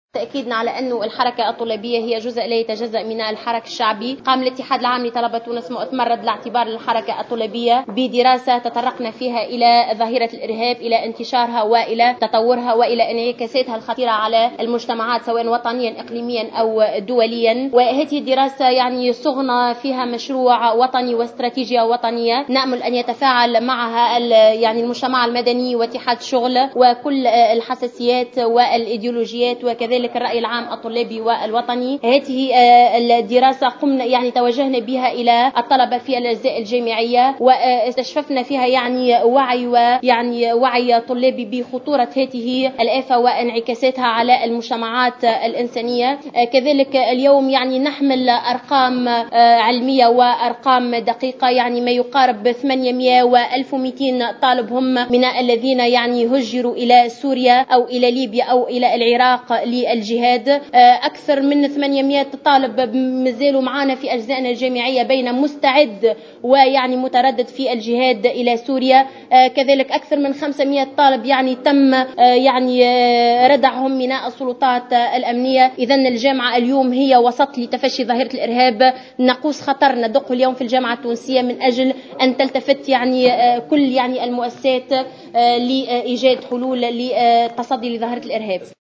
عقد المكتبُ التنفيذي للإتحّاد العام لطلبة تونس اليوْم الخميس ندوة صحفية واكبها...